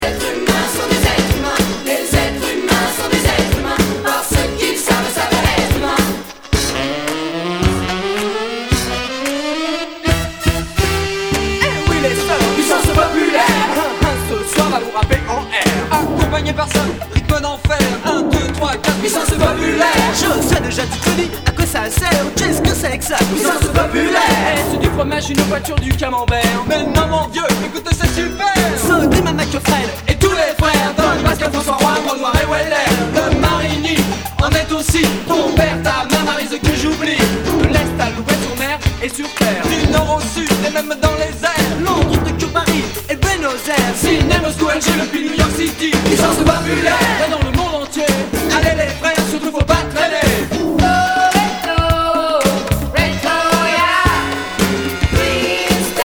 SOUL/FUNK/DISCO
ナイス！フレンチ・ファンク！